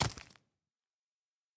sounds / mob / zombie / step5.mp3